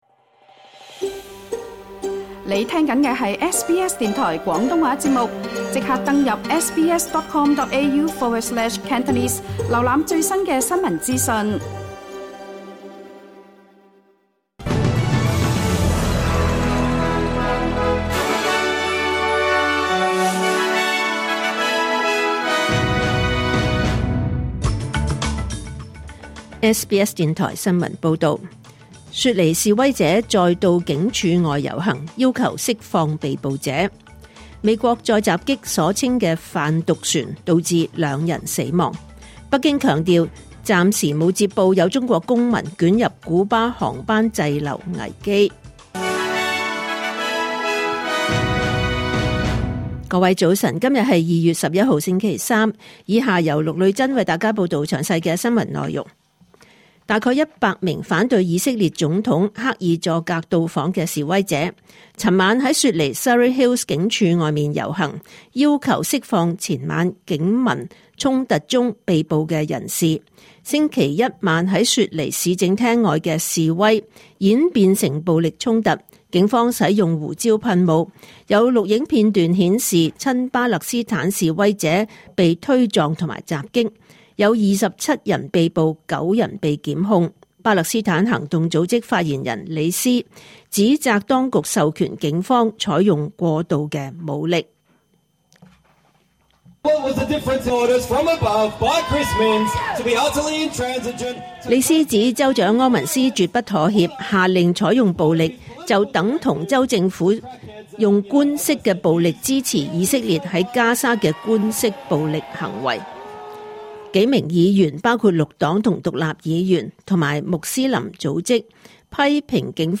2026 年 2 月 11 日SBS廣東話節目九點半新聞報道。